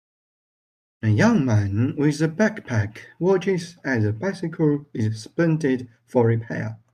Pronounced as (IPA) /ˈwɑt͡ʃɪz/